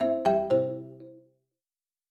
ringtone.wav